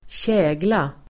Uttal: [²tj'ä:gla]